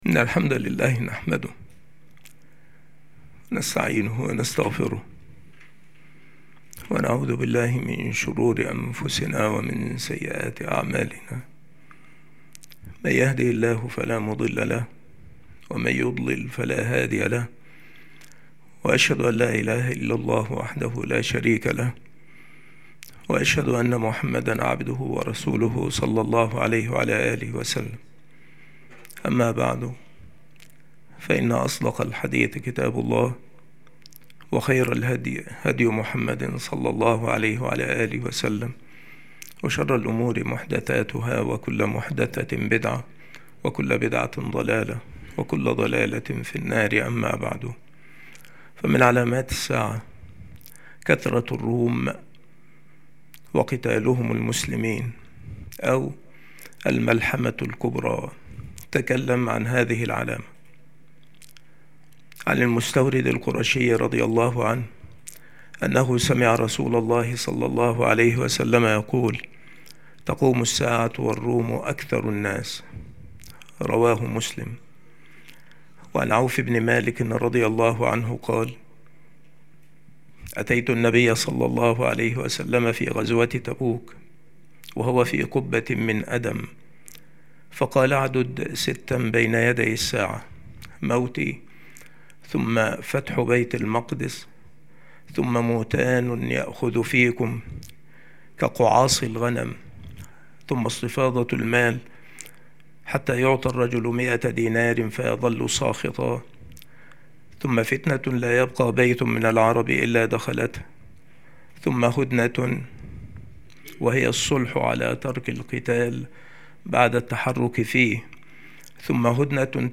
هذه المحاضرة